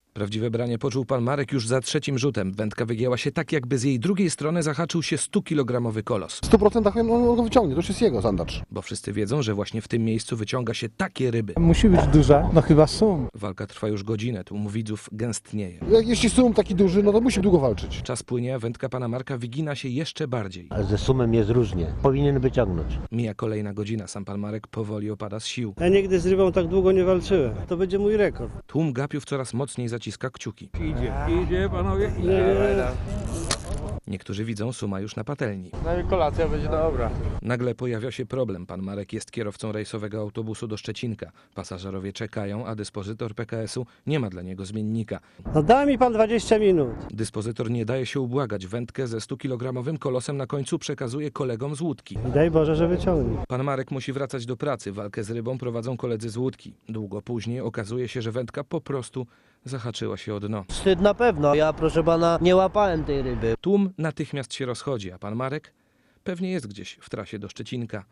(RadioZet) Źródło